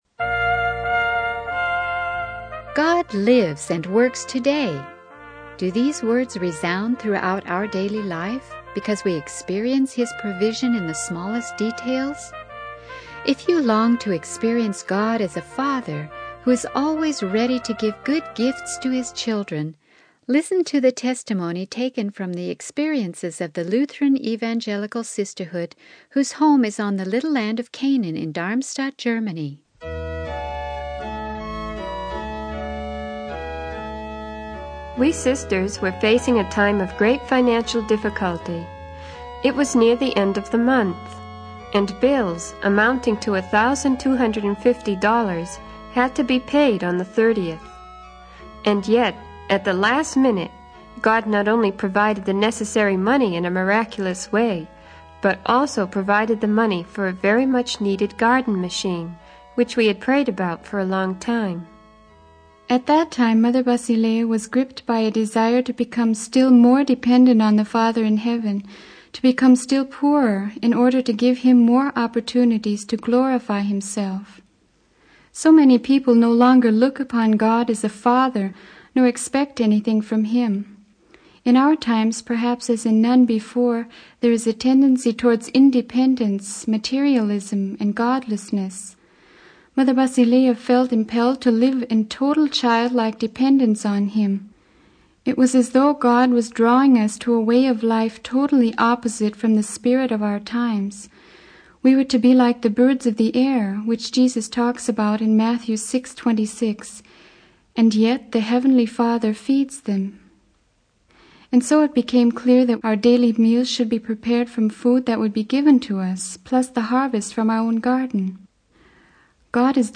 The sermon emphasizes the importance of trusting in God's provision and living in childlike dependence on Him, leading to experiencing His blessings in daily life.